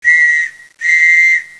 Whistle Sound Bite Library
Soundbites of  the most popular whistles